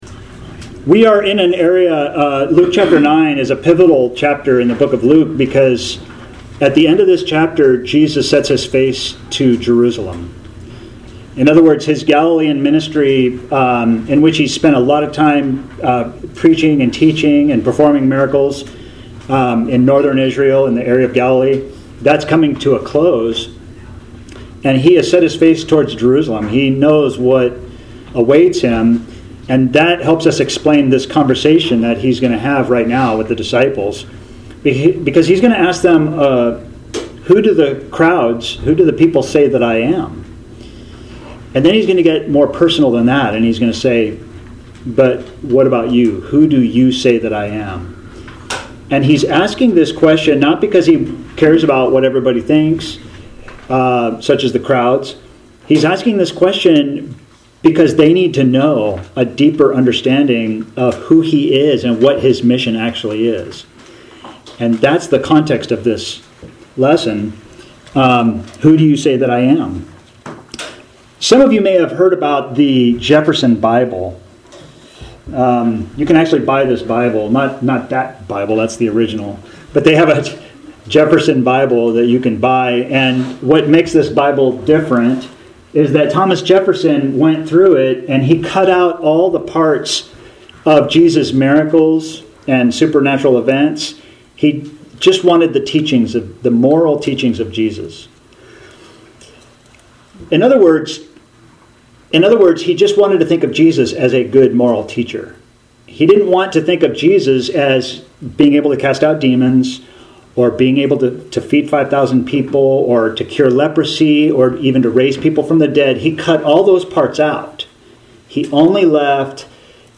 Author jstchurchofchrist Posted on September 7, 2023 Categories Sermons Tags Jesus , Luke - Gospel For All